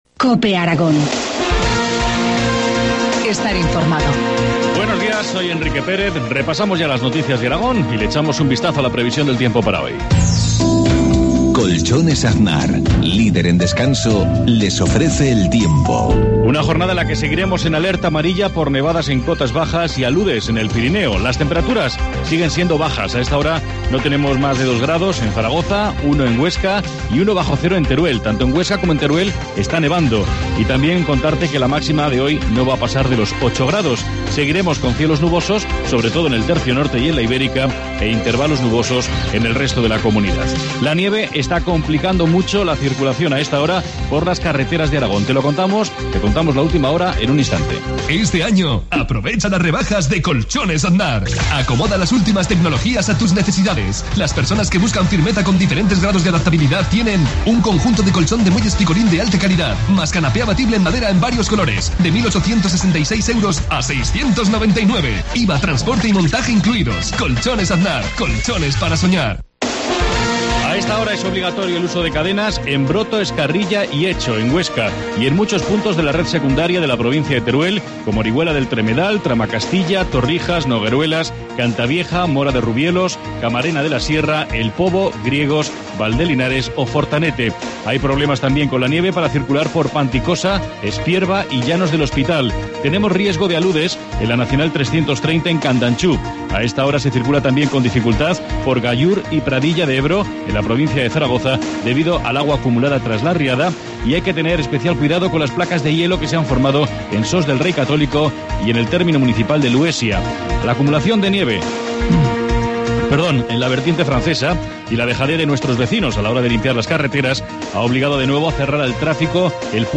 Informativo matinal, miércoles 23 de enero, 7.53 horas